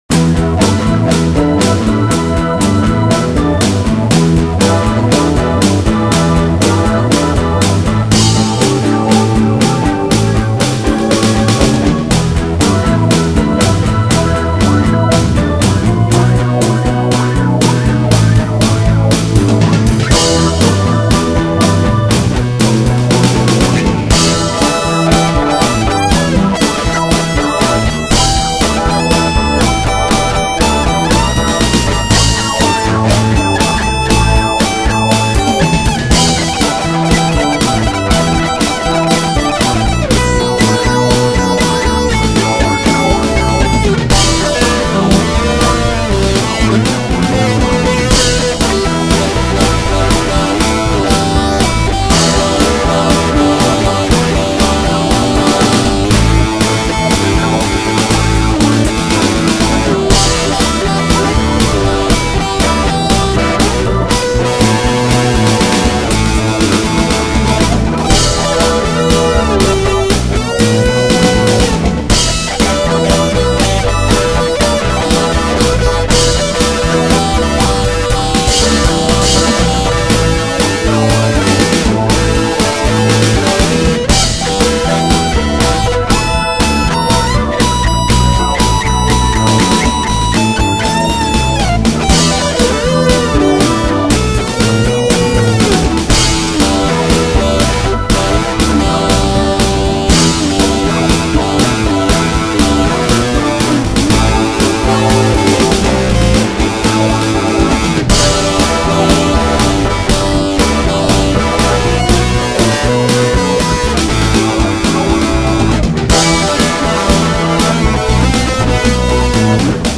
Blues métal qui envoie la patate. 2004